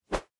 Whoosh B.wav